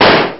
bulletshoot.ogg